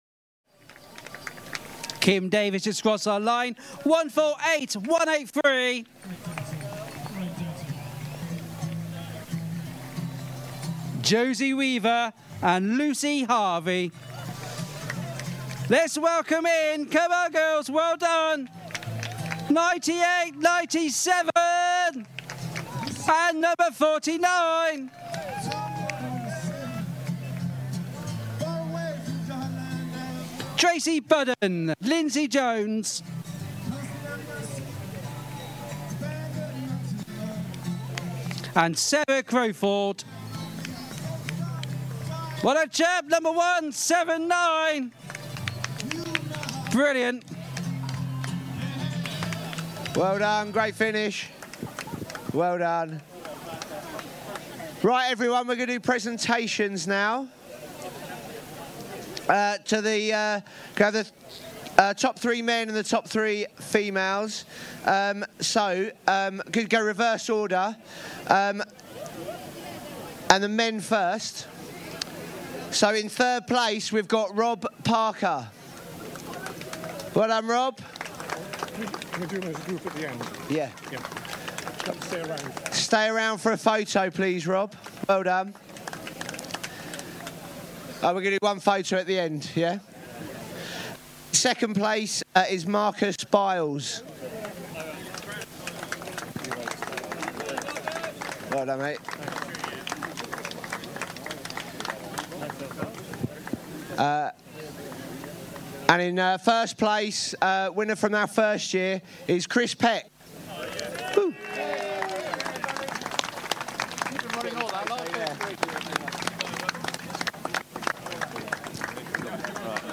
Reporting from the Hardy Half plus pictures
The annual Hardy Half Marathon was held at Dorchester Rugby Club on 11th May, another successful event with around 270 runners taking part.
Here’s an excerpt from the proceedings…